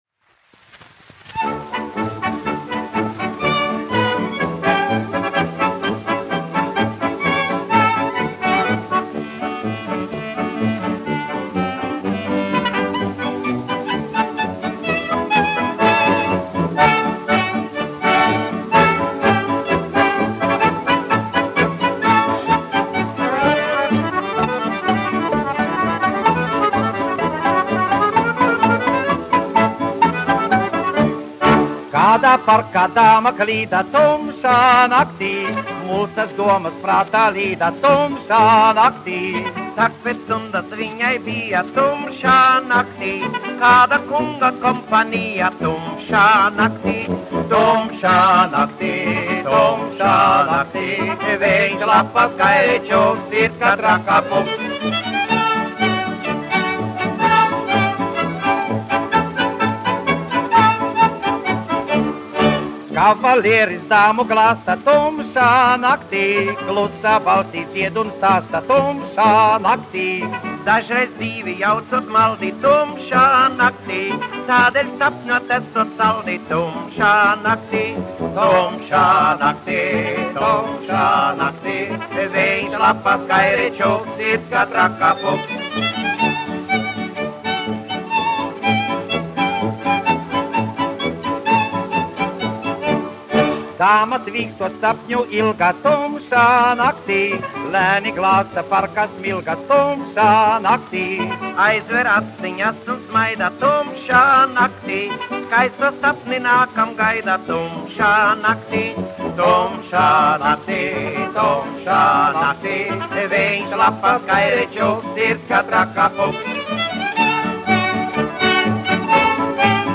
1 skpl. : analogs, 78 apgr/min, mono ; 25 cm
Populārā mūzika -- Latvija
Fokstroti
Skaņuplate